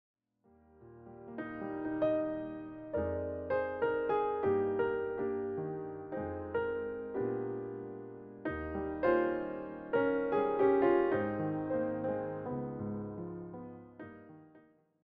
With its steady, expressive piano style